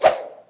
SFX_falloEspada1.wav